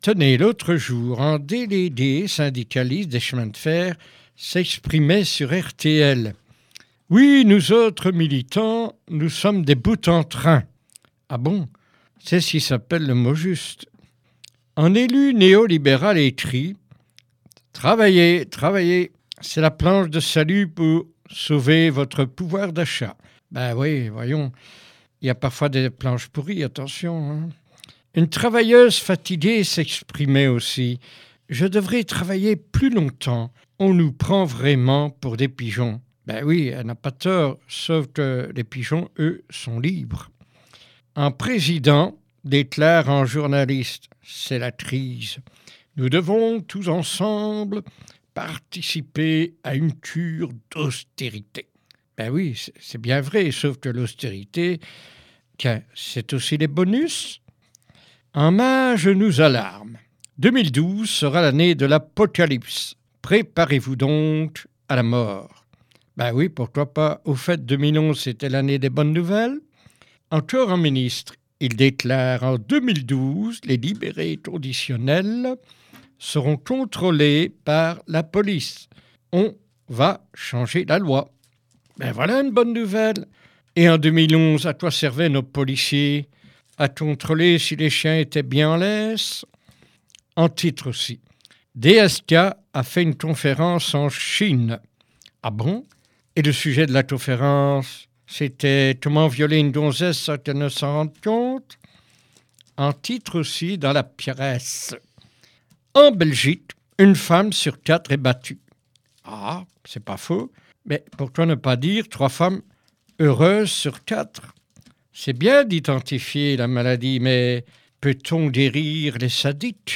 Revue de presse
jp1-revue_de_presse.mp3